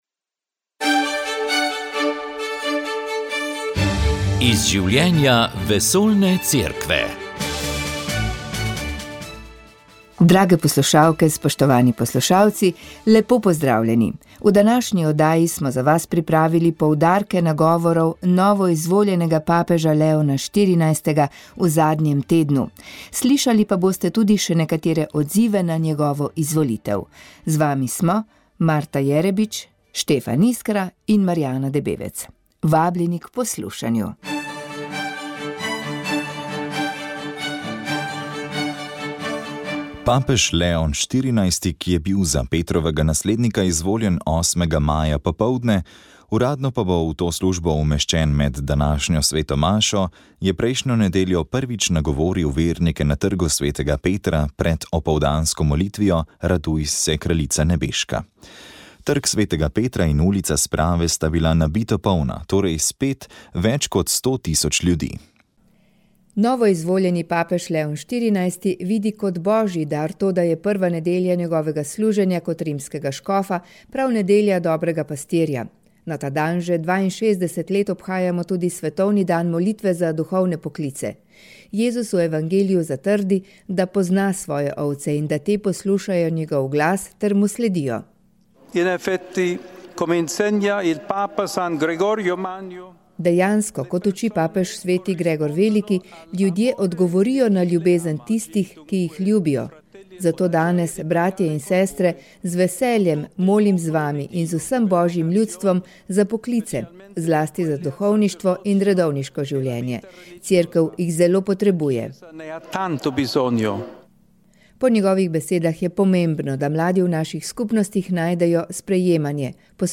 smo zavrteli nekaj afriških napevov iz Zambije in Južnoafriške republike.